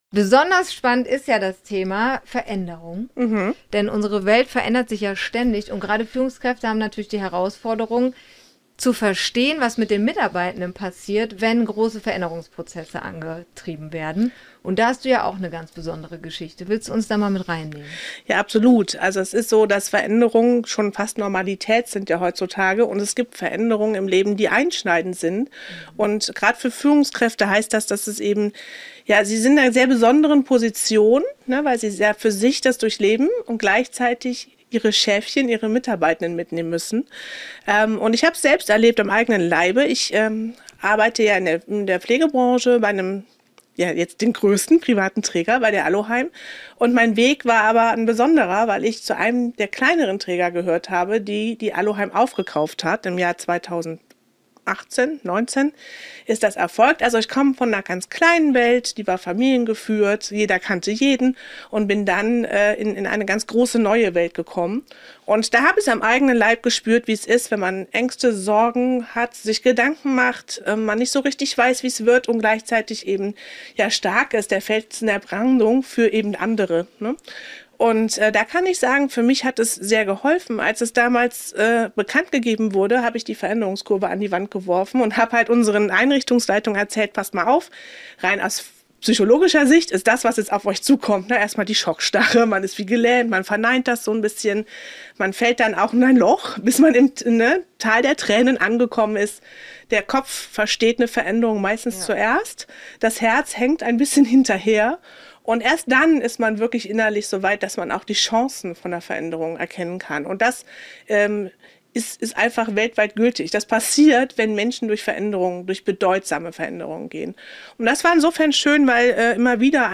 Gedreht wurde im Eventflugzeug auf dem euronova Campus in Hürth.